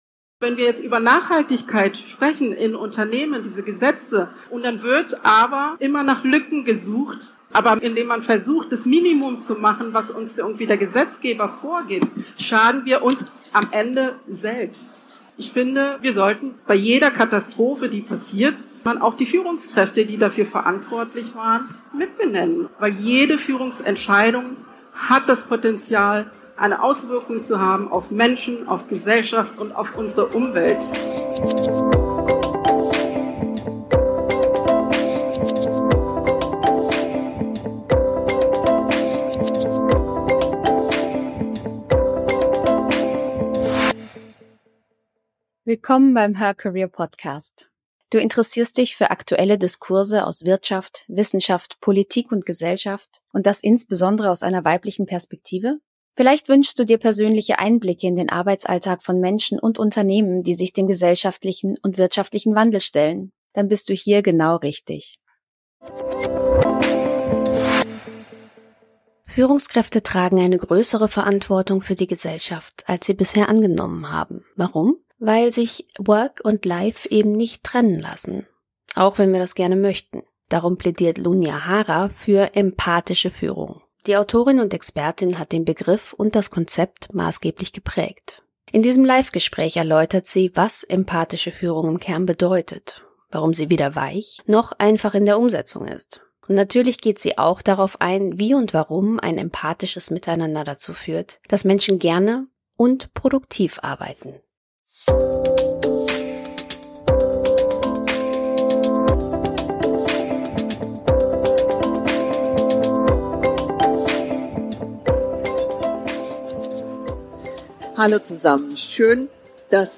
In diesem Live-Gespräch erläutert sie, wie die vier Säulen Menschlichkeit, Offenheit, Feedback und Selbstreflexion des Konzepts dazu führen, dass Menschen gerne und produktiv arbeiten.